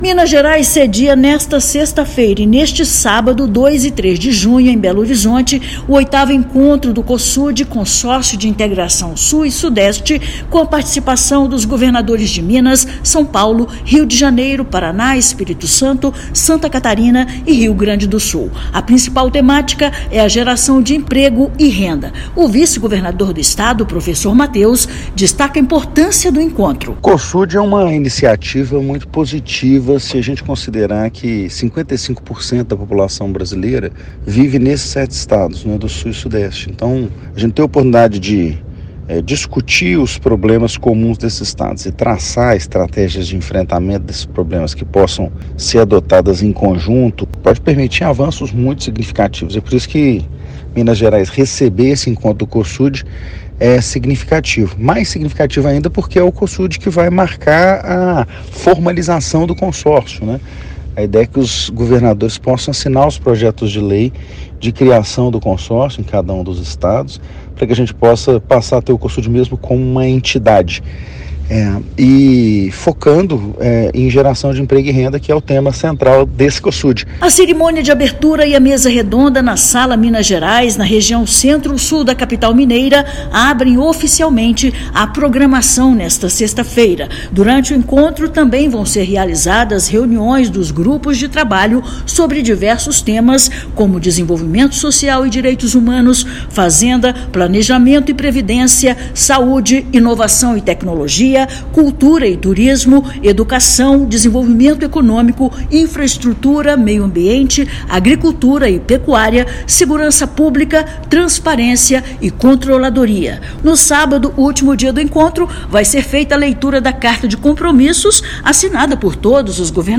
Criado em 2019, a partir de reunião organizada pelo governador mineiro, Cosud projeta formalização para avançar em pautas estratégicas para as duas regiões; geração de emprego e renda é tema central. Ouça matéria de rádio.